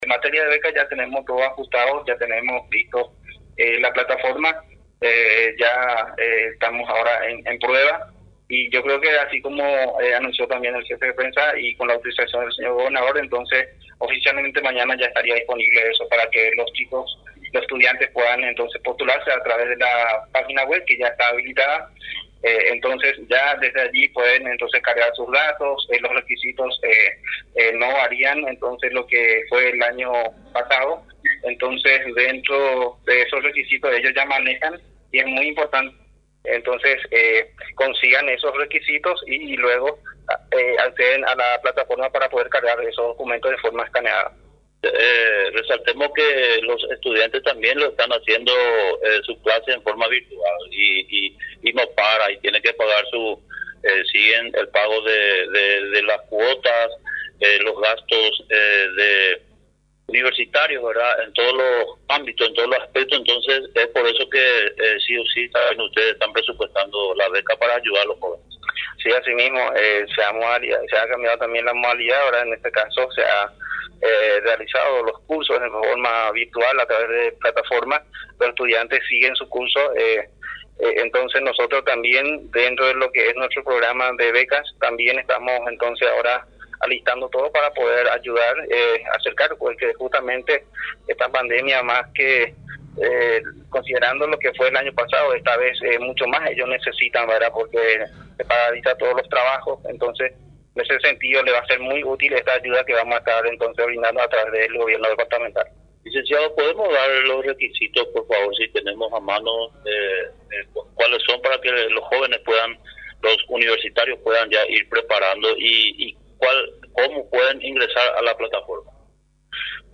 Explico, en conversación con Radio Nacional San Pedro, que los interesados, deben llenar unos requisitos a través de unos formularios.